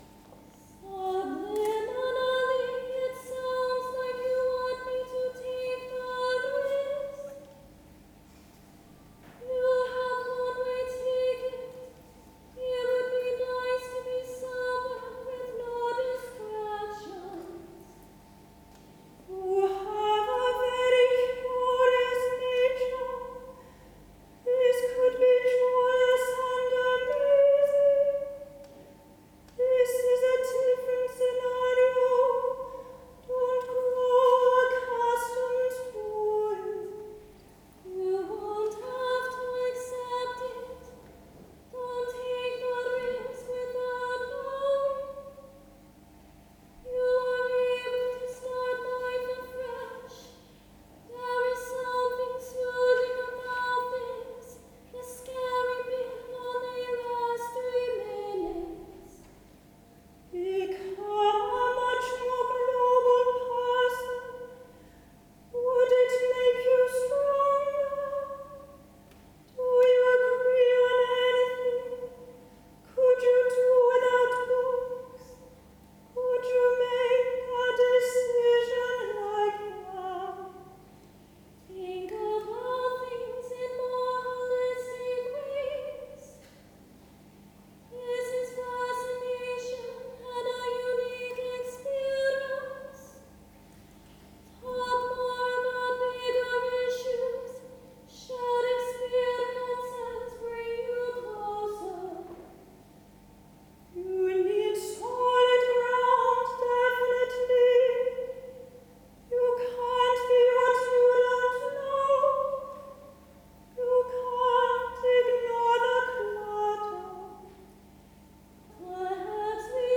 One Way Chorus exists as an online text piece, with maxims appearing at short intervals in random Hubble telescope colours, and as a sound-piece on a digital radio, with the texts sung in plainchant.
at the Ruskin Gallery, ARU